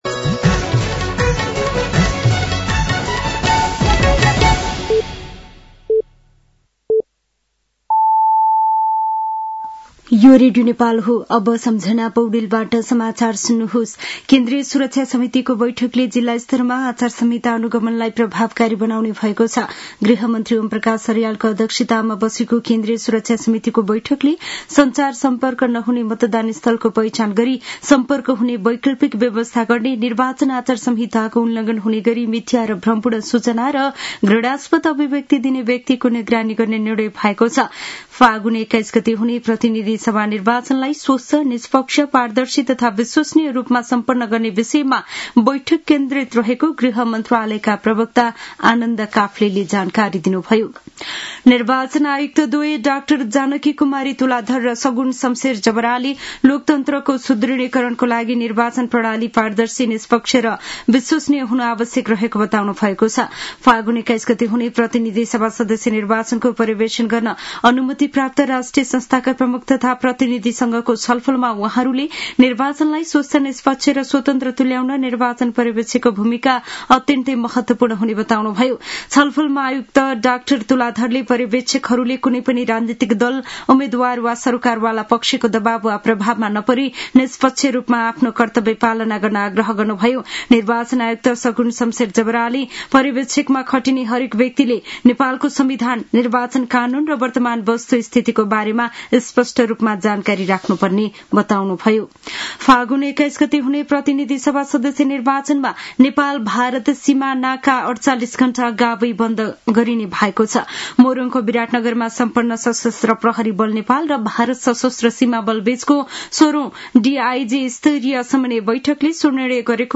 साँझ ५ बजेको नेपाली समाचार : २४ माघ , २०८२
5.-pm-nepali-news-1-2.mp3